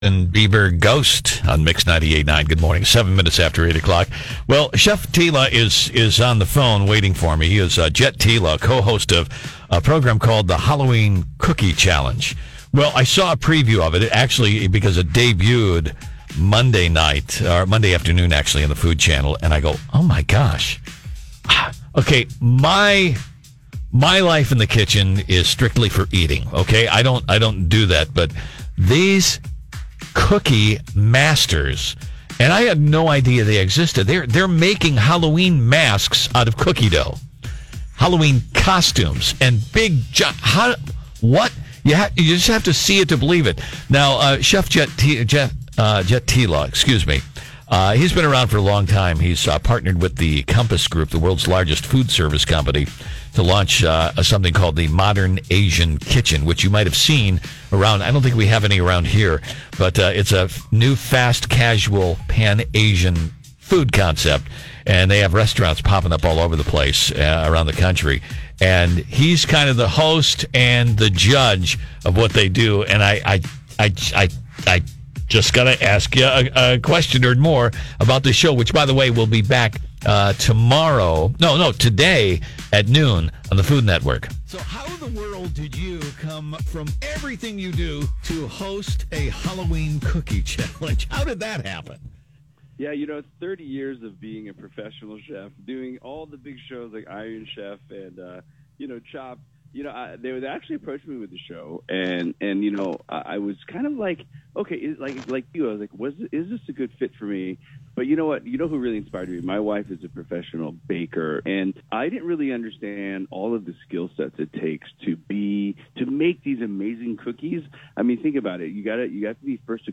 THE INTERVIEW: https